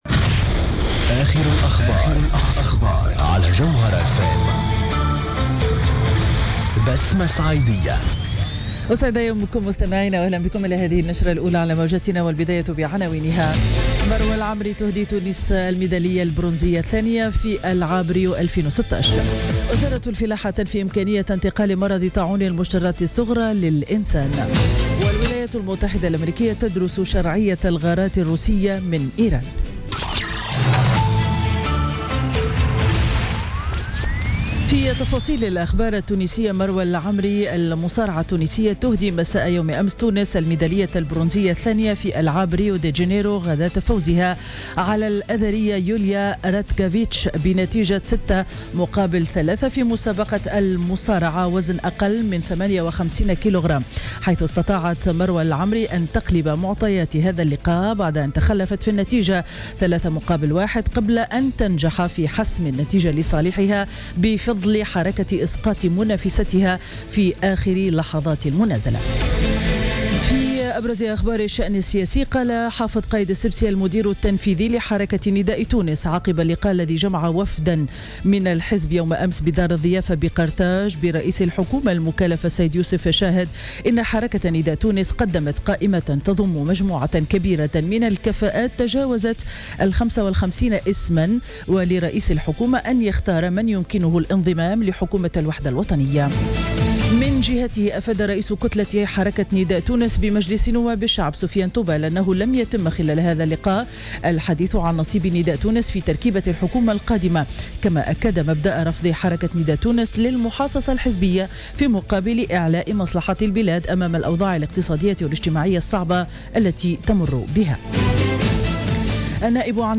نشرة أخبار السابعة صباحا ليوم الخميس 18 أوت 2016